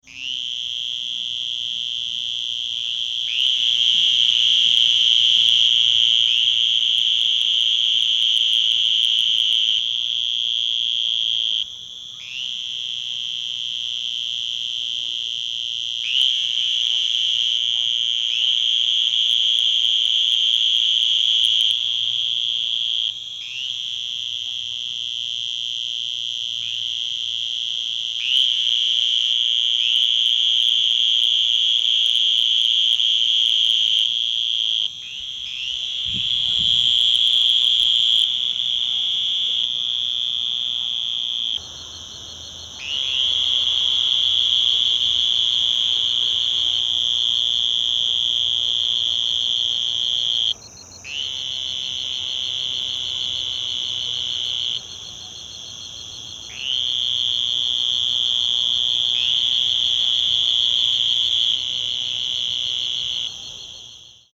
Advertisement Calls
Sound  This is a 64 second recording of an advertisement chorus of a group of Western Green Toads calling at night from a shallow rain pool in Cochise County, Arizona in August.